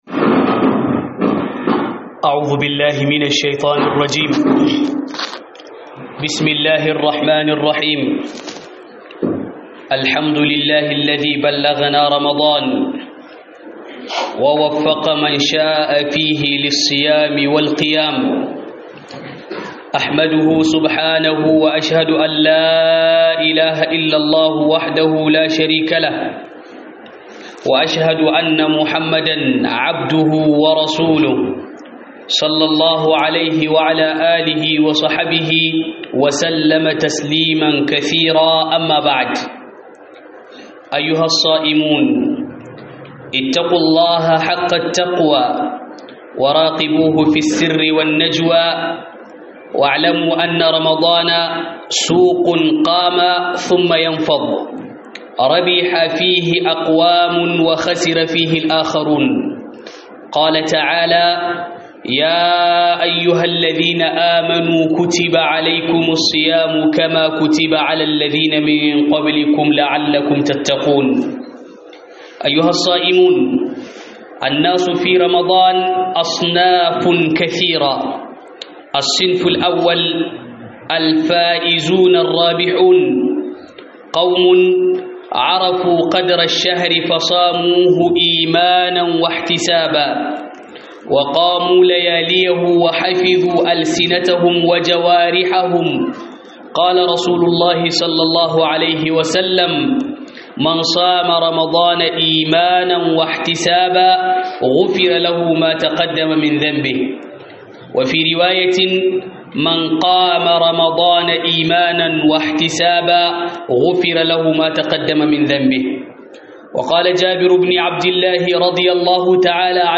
Huɗuba akan mutanè acikin Ramadana - HUDUBA